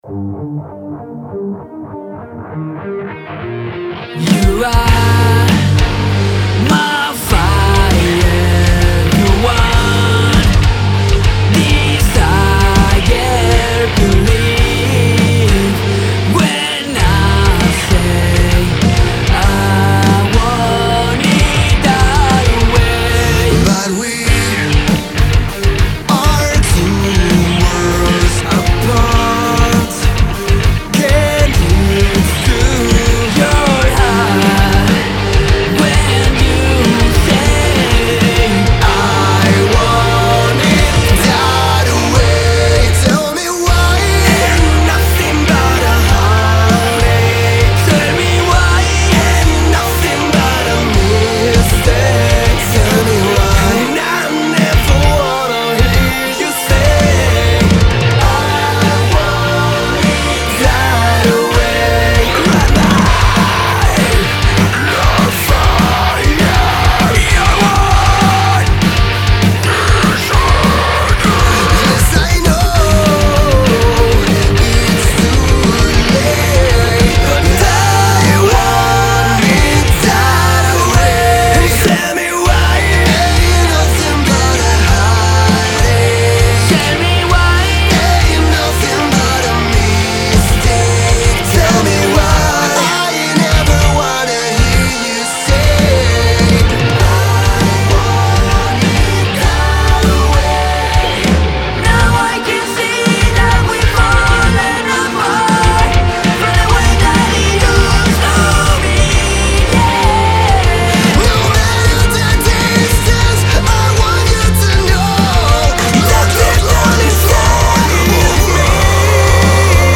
Metal cover